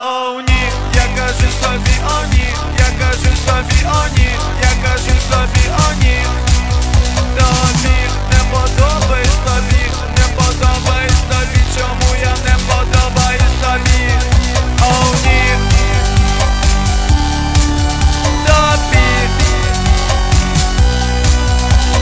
• Качество: 128, Stereo
Synth Pop
басы